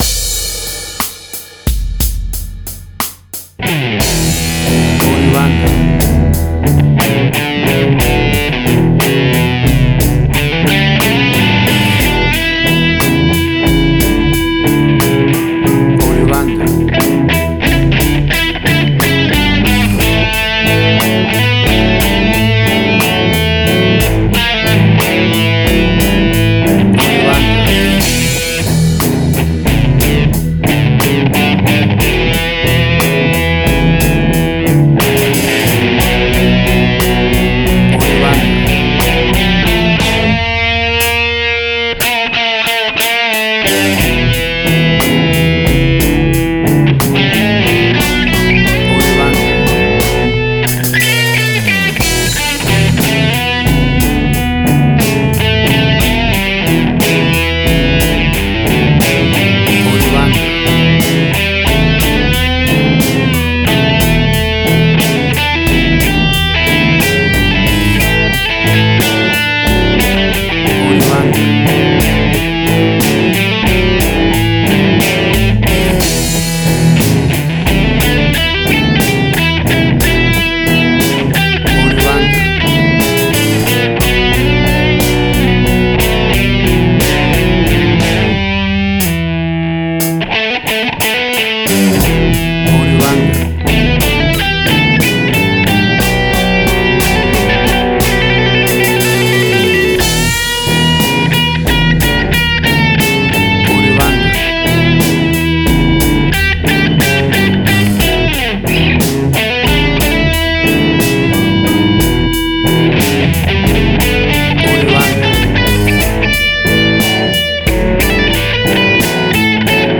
WAV Sample Rate: 16-Bit stereo, 44.1 kHz
Tempo (BPM): 60